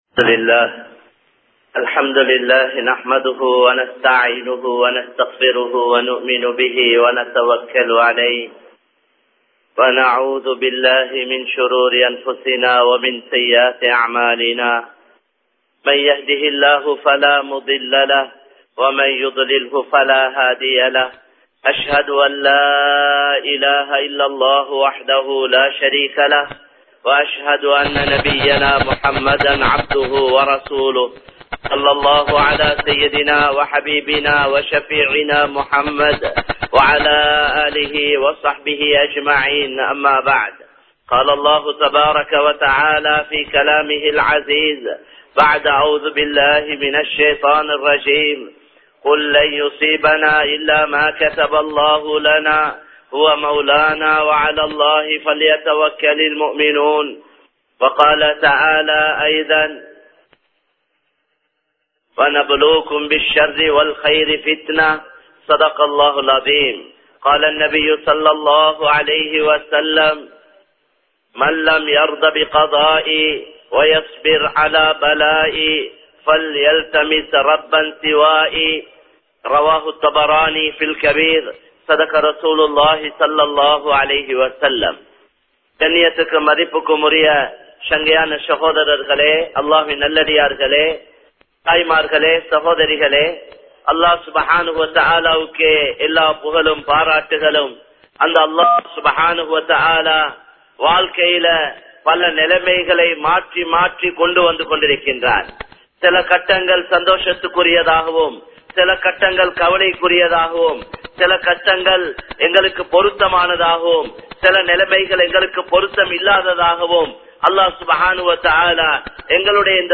அல்லாஹ் எம்மை ஏன் சோதிக்கின்றான்? (Why does Allah Test us?) | Audio Bayans | All Ceylon Muslim Youth Community | Addalaichenai
Kollupitty Jumua Masjith